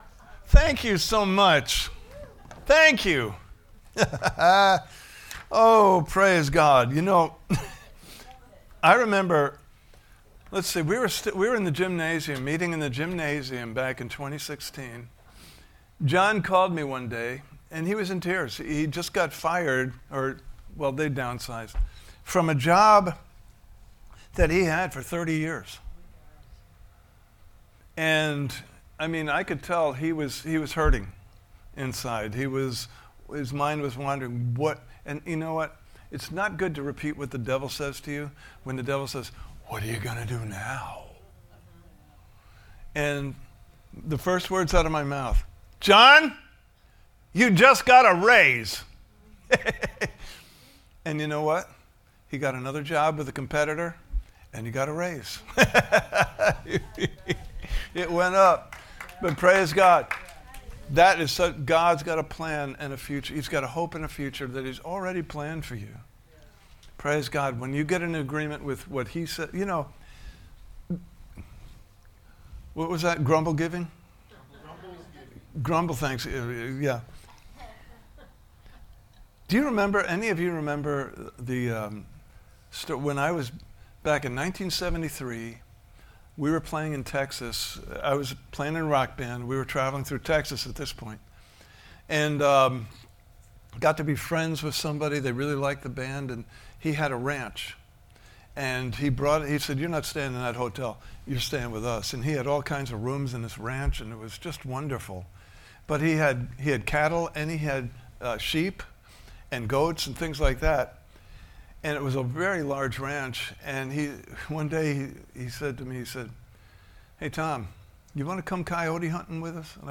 Service Type: Sunday Morning Service « Part 4: He is the God of Increase!